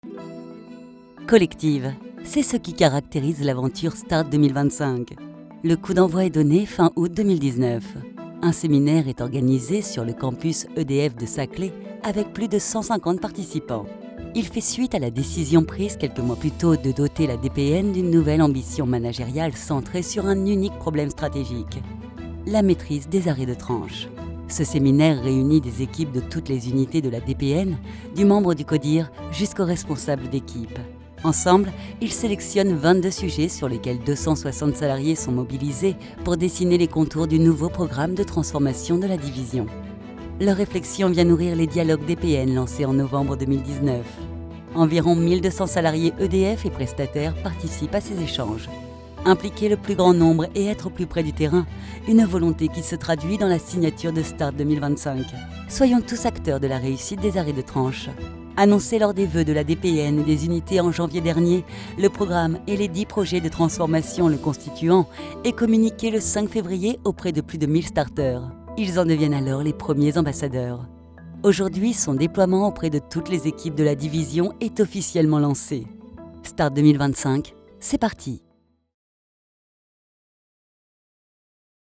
Natural, Reliable, Mature, Friendly
Corporate